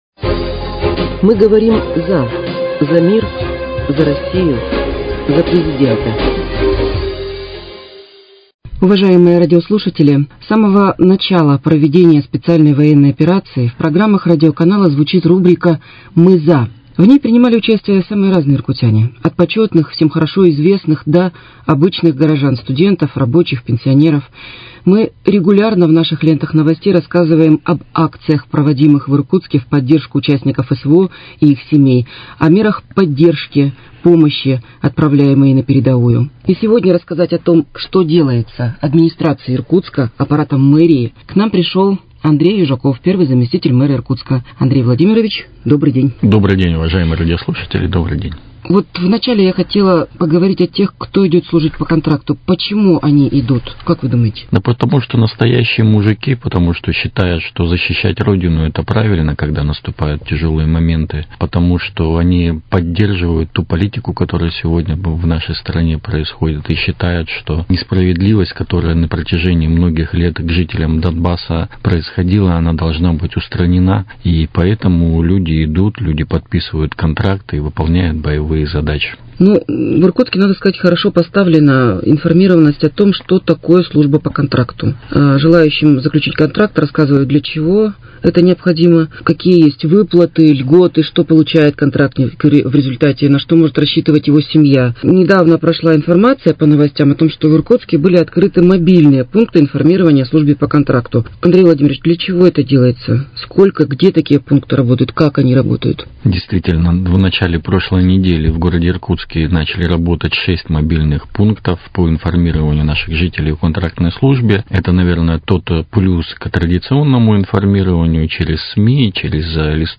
беседует с первым заместителем мэра Иркутска Андреем Южаковым